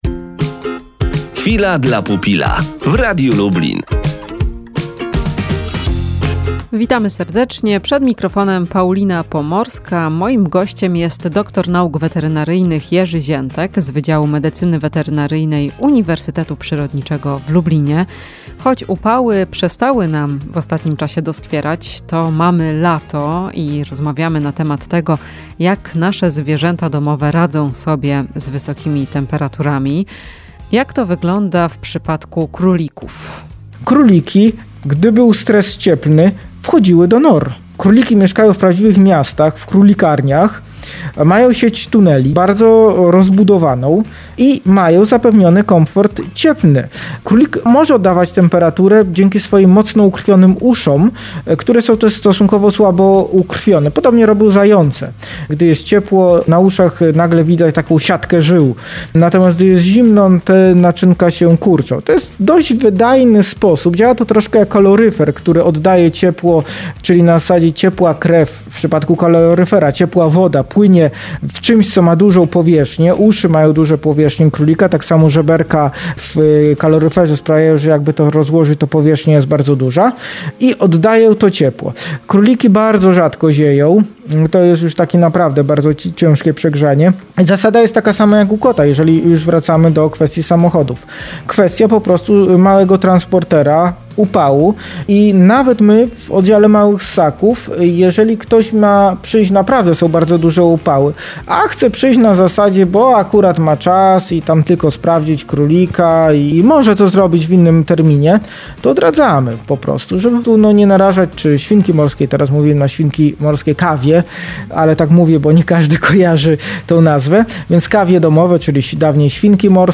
W „Chwili dla pupila” powiemy, jak z wysokimi temperaturami radzą sobie króliki i kawie domowe. Rozmowa z dr. n. wet.